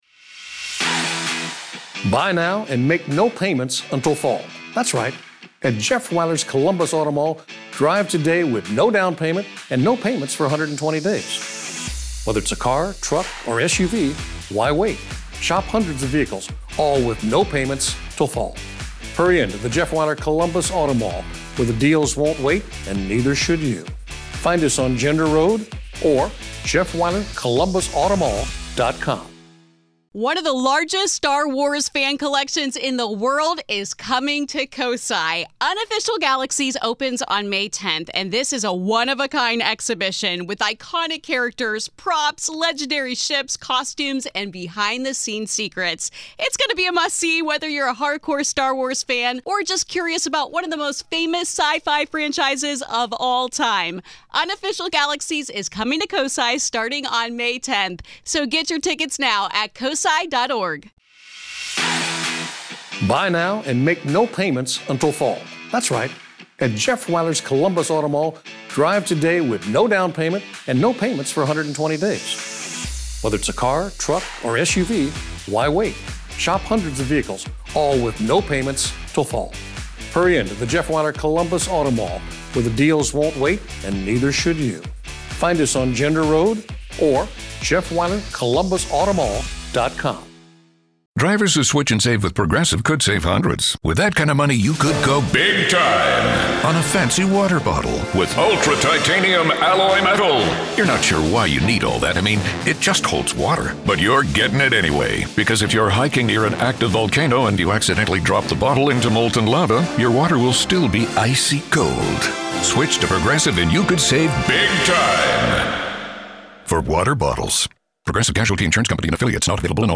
In this episode, one caller recalls the terrifying moment she knew she was about to seriously injure—or possibly kill—herself in a fall, only to feel an undeniable force redirect her mid-flight.